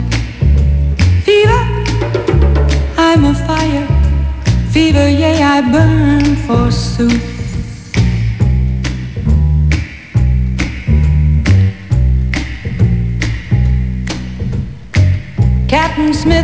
Le tuner FM
La qualité de réception est bonne voir excellente lorsqu'on reçoit en stéréo.
L'enregistrement de la radio est possible tout en l'écoutant !